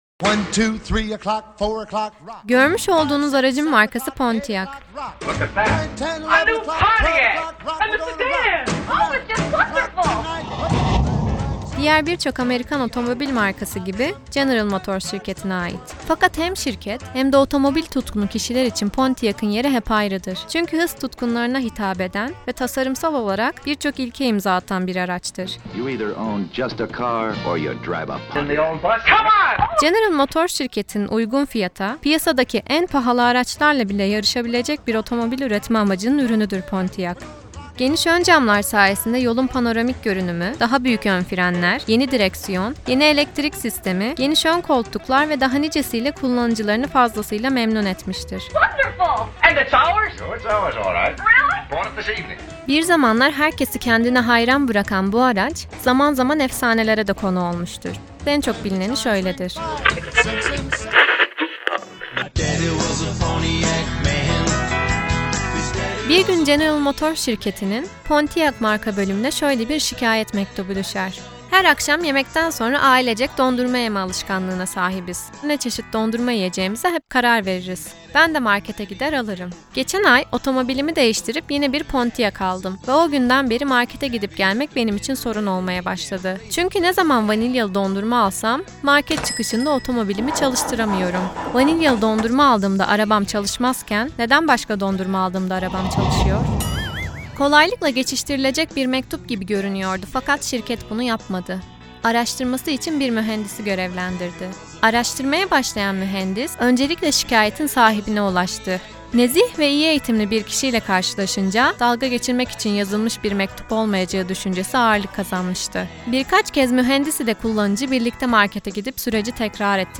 Anadol 1975 ANADOL 1975 Sesli Rehber Örneği Pontıac 1955 PONTIAC 1955 Sesli Rehber Örneği